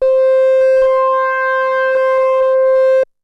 TALKING OSC 5.wav